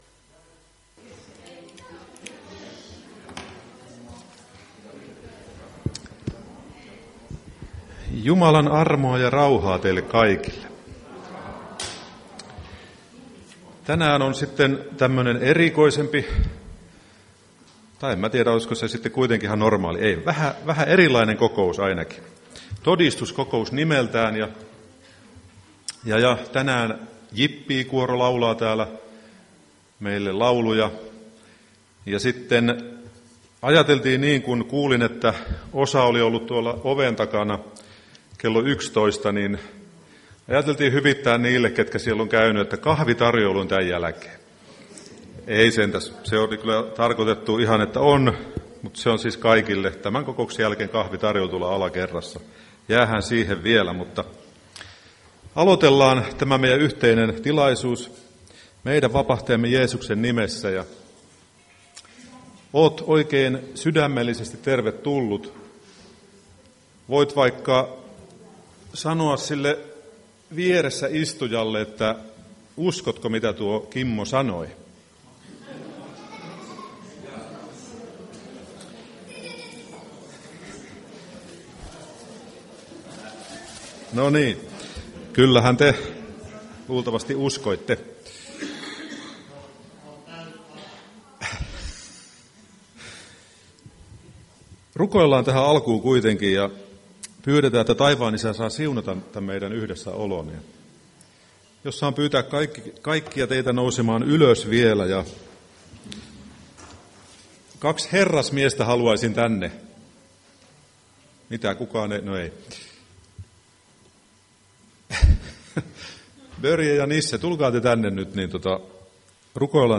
Todistuskokous 23.2.2025